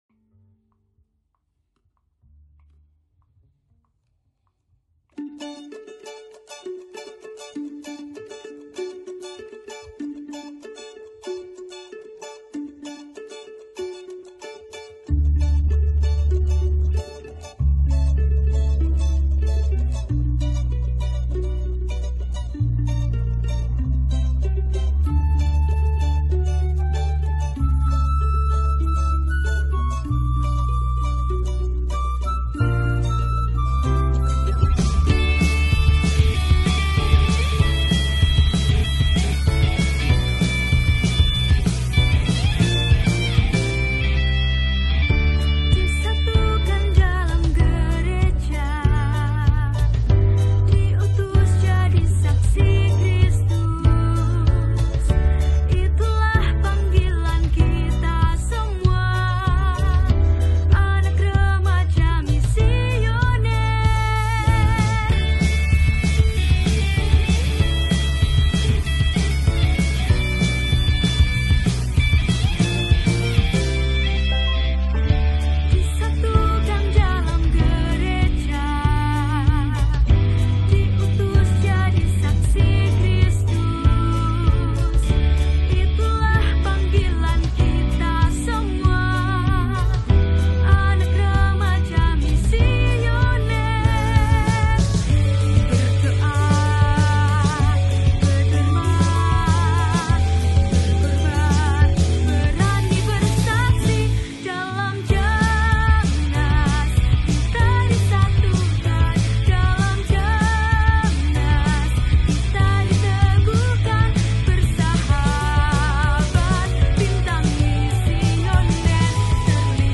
Theme Song JAMNAS SEKAMI 2023